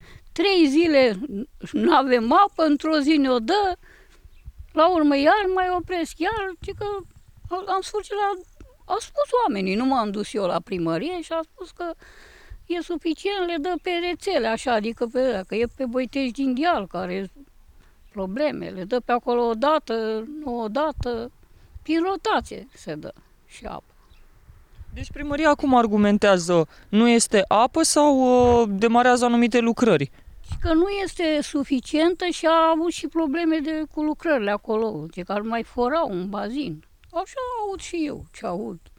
Cetățeni, Comuna Bălănești